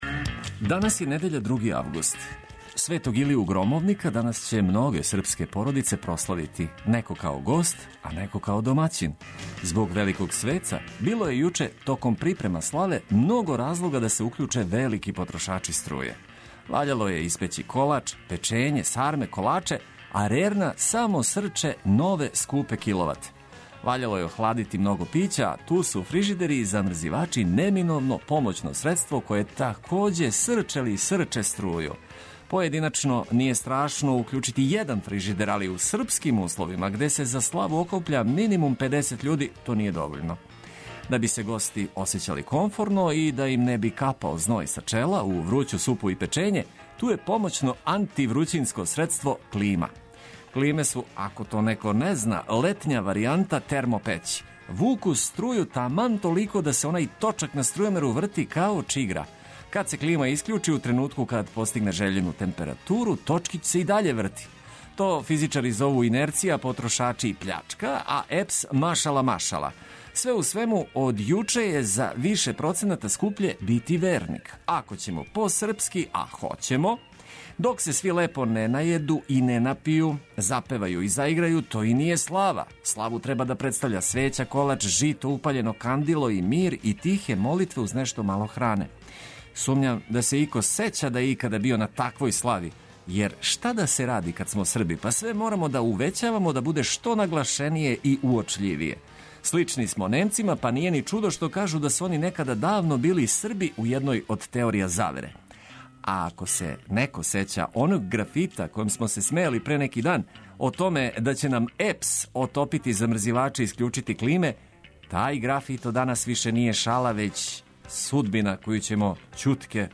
Нека ова недеља почне уз добру музику и информације од користи. Врела музика потераће и најсањивије да устану упркос нерадном дану.